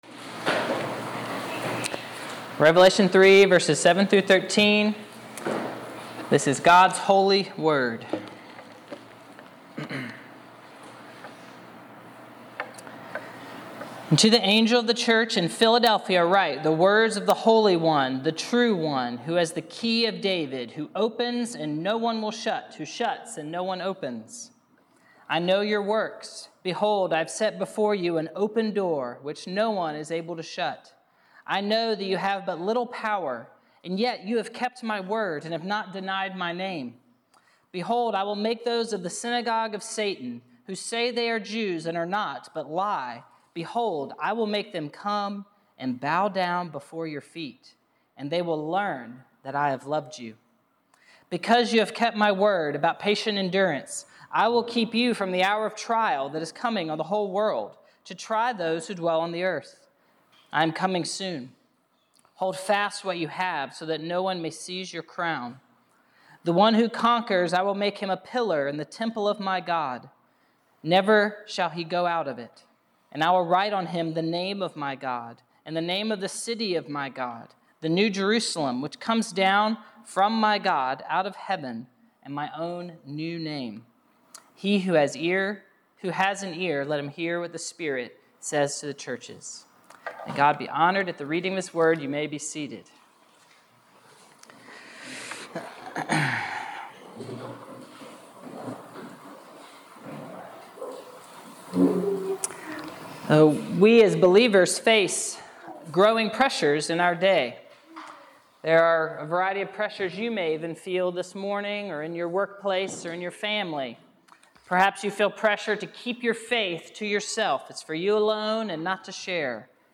Passage: Revelation 3:7-13 Preacher